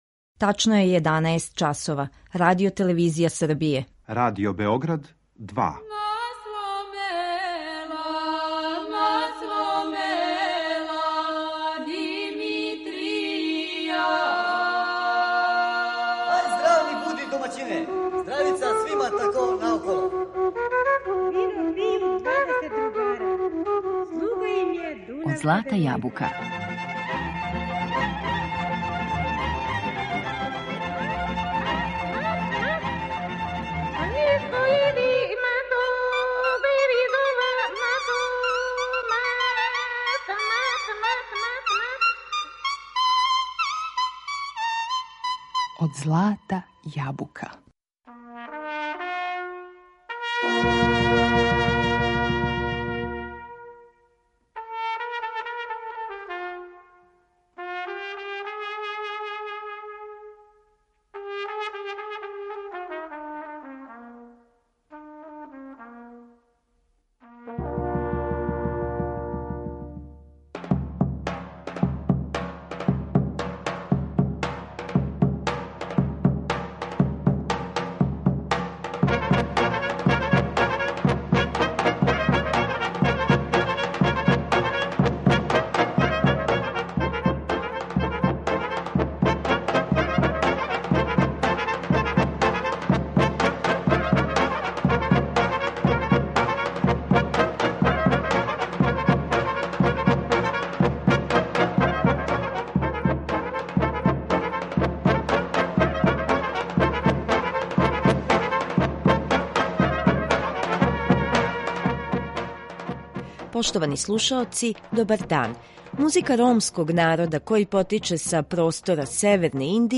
Ромска музика Балкана
Ромска музика је увек обележена националним или регионалним карактеристикама простора који насељавају, па ипак, има нешто што заједнички израз чини посебним и препознатљивим. Данашње издање емисије Од злата јабука посвећено је управо балканској ромској музици.